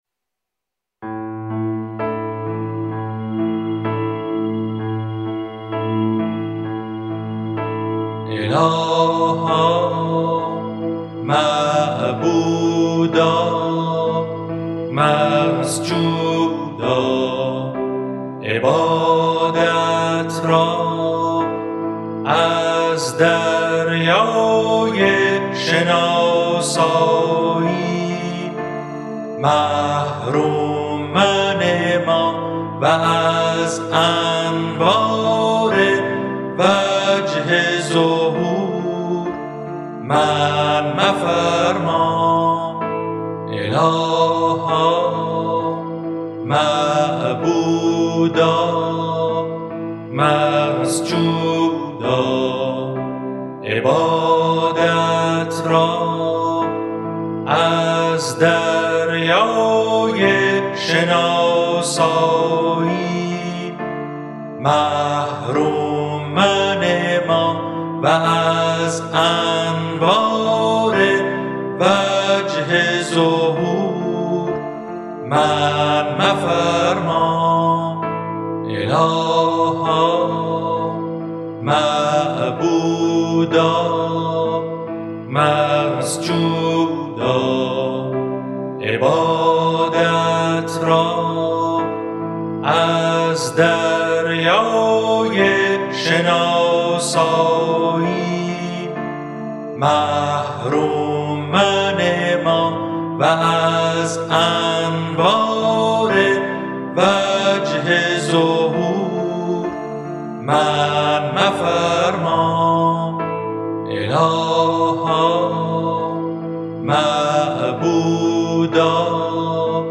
Download Track9.mp3 سایر دسته بندیها اذکار فارسی (آوازهای خوش جانان) 12068 reads Add new comment Your name Subject دیدگاه * More information about text formats What code is in the image?